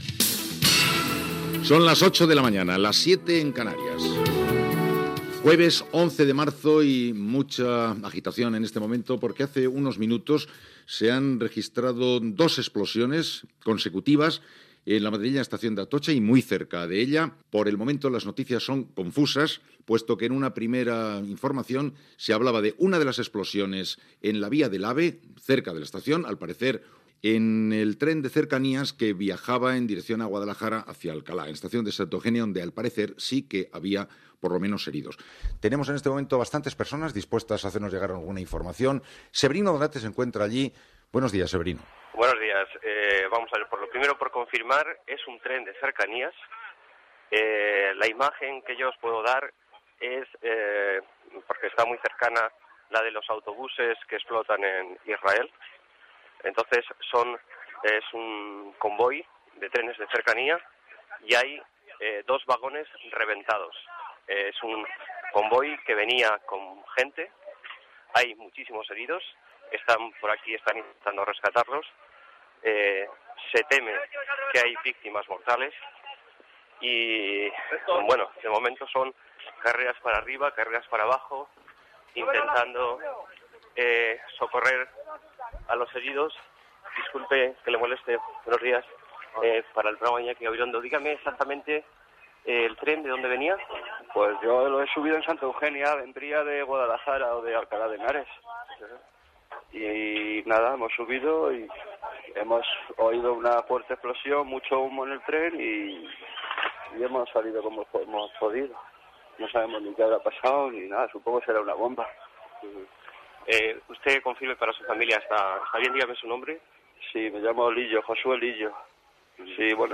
ab53850d18f0d3d59d8ded5489eff41c9978c773.mp3 Títol Cadena SER Emissora Ràdio Barcelona Cadena SER Titularitat Privada estatal Nom programa Hoy por hoy Descripció Hora, data, primera informació, a la franja de les 8 del matí, d'un parell d'explosions en trens de rodalies a l'estació d'Atocha de Madrid. Explicacions de dos testimonis.
Publicitat, hora, trucada d'una testimoni dels fets, informació des de l'estació d'Atocha i des de la de Santa Eugenia.
Hora i publicitat. Entrevista a un afectat.
Info-entreteniment